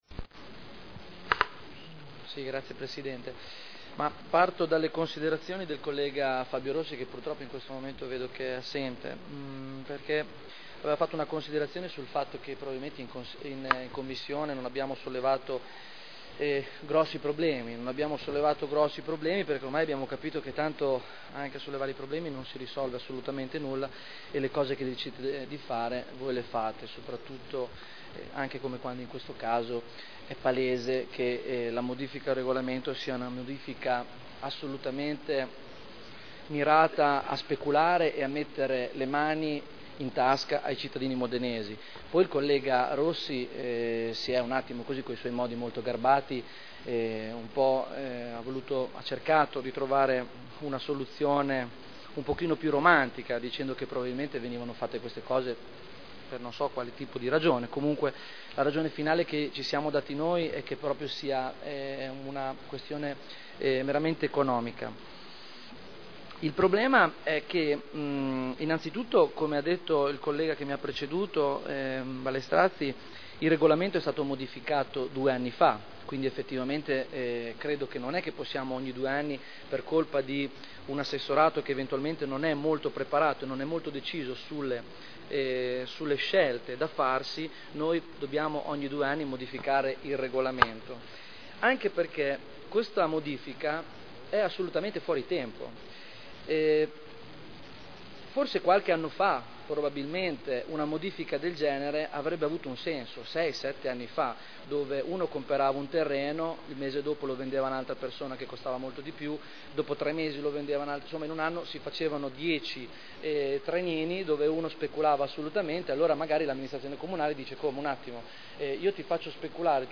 Stefano Barberini — Sito Audio Consiglio Comunale
Seduta del 6/12/2010. Dibattito su delibera: Art. 14.1 del testo coordinato delle norme di PSC (Piano Strutturale Comunale) POC (Piano Operativo Comunale) RUE (Regolamento Urbanistico Edilizio) – Modifiche al regolamento attuativo – Approvazione (Commissione consiliare del 30 novembre 2010)